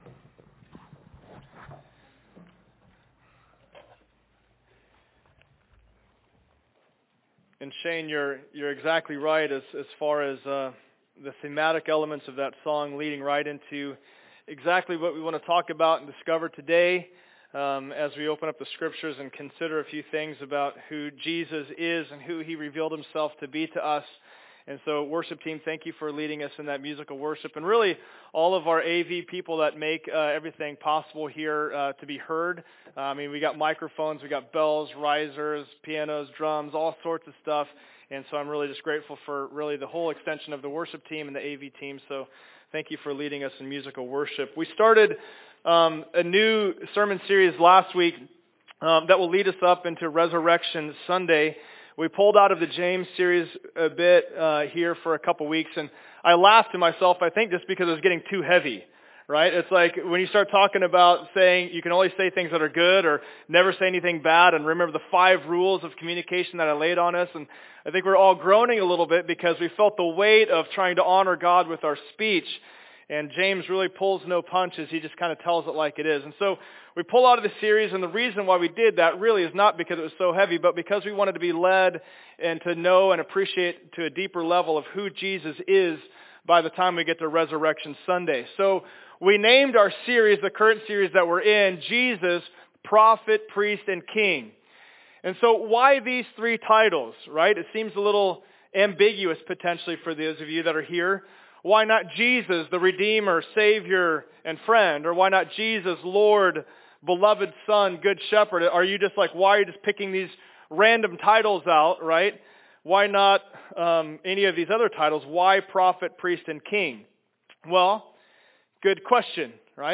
King Service Type: Sunday Service Download Files Notes « Jesus